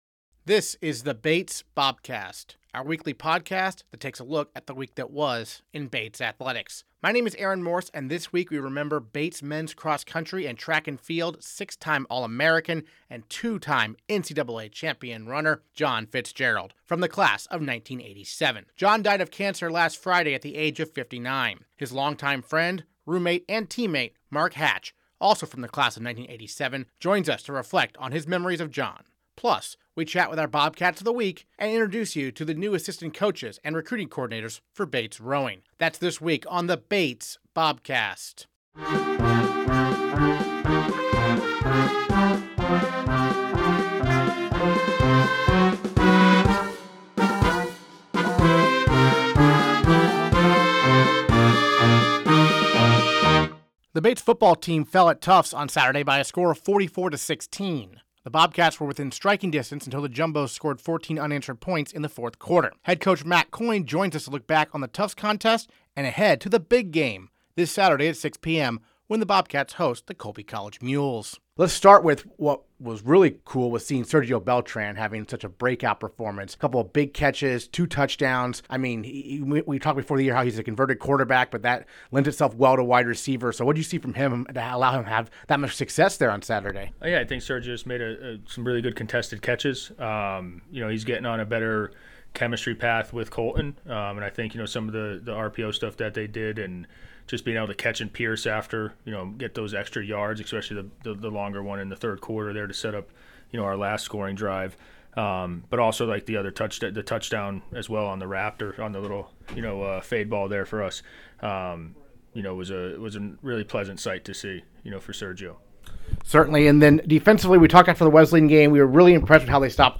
Plus, we chat with our Bobcats of the Week and introduce you to the new assistant coaches and recruiting coordinators for Bates rowing.